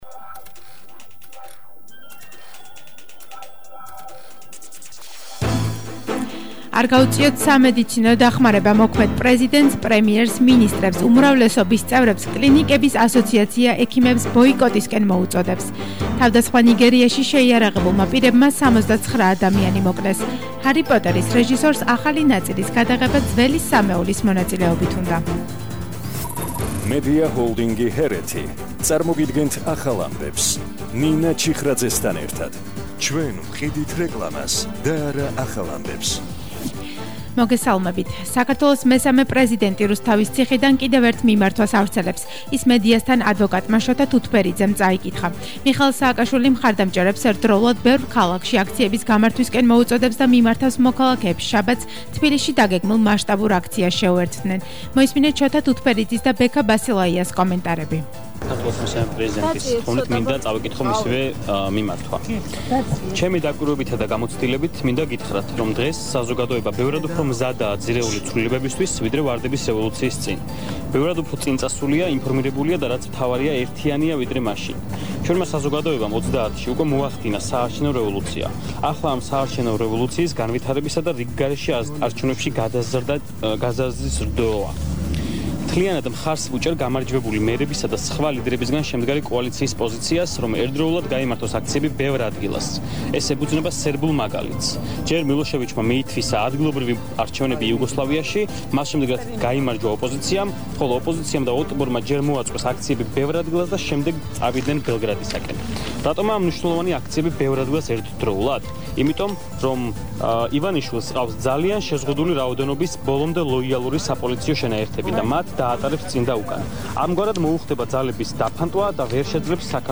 ახალი ამბები 16:00 საათზე –5/11/21